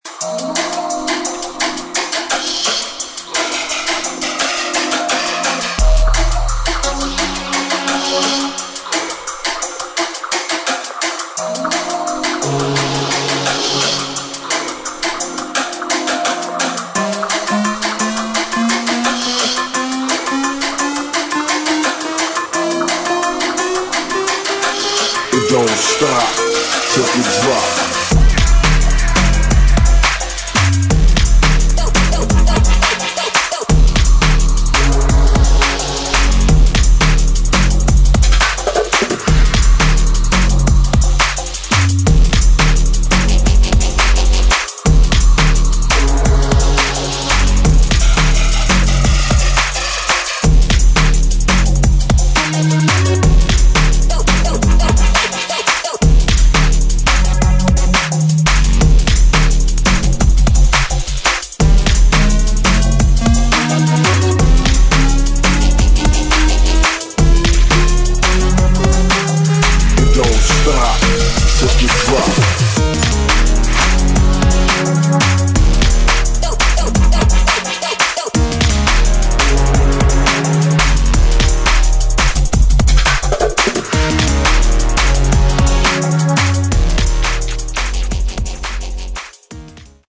[ DRUM'N'BASS / JUNGLE ]